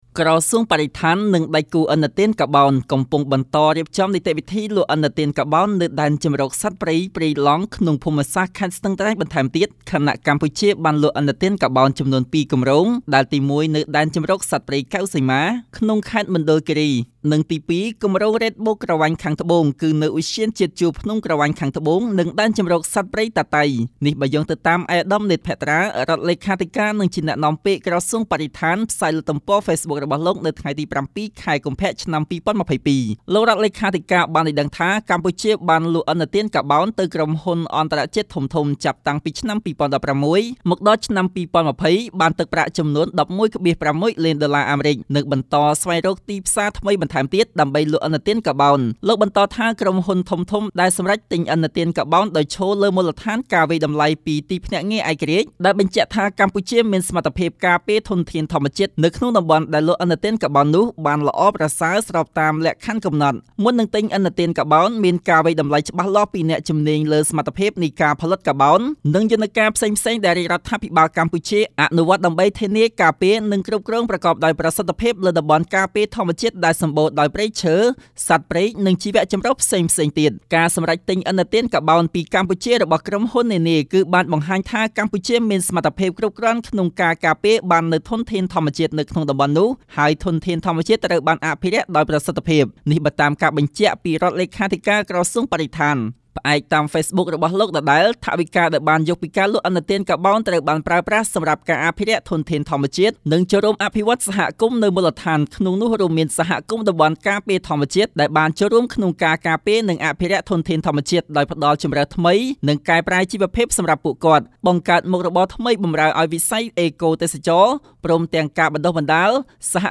ជូននូវសេចក្ដីរាយការណ៍៖